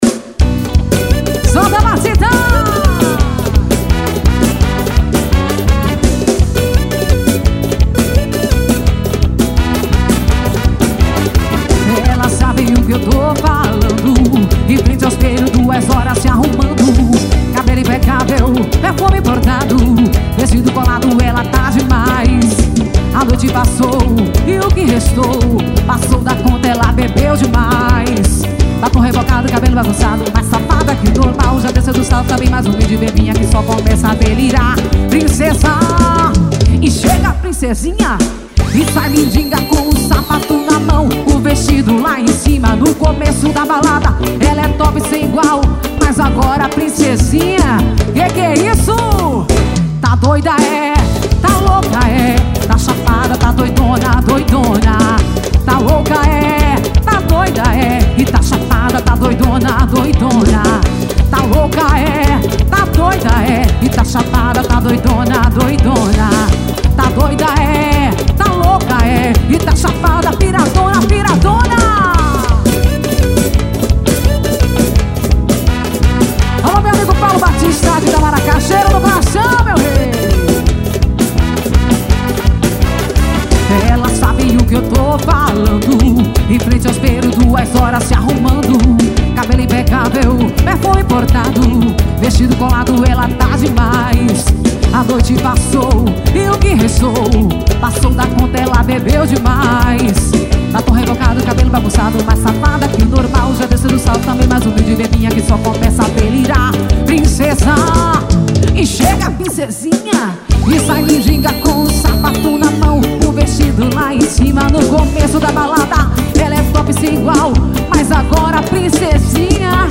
axe.